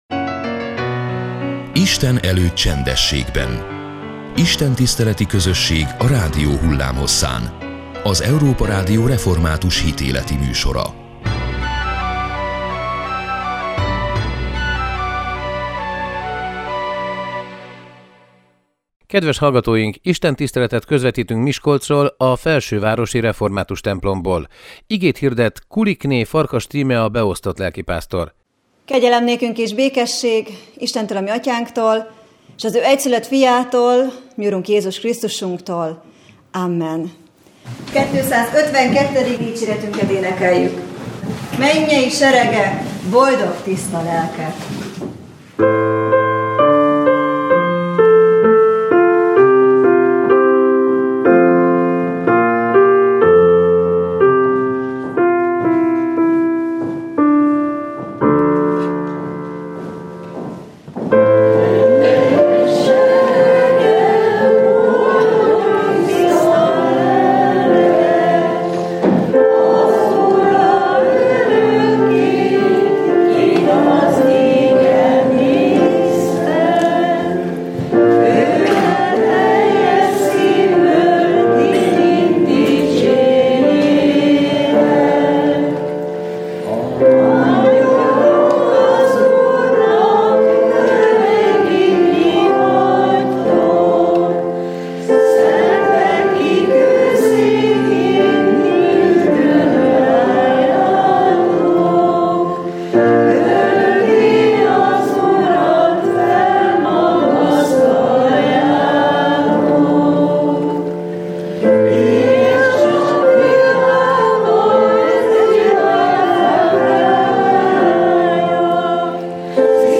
Istentiszteletet közvetítettünk Miskolcról, a felsővárosi református templomból.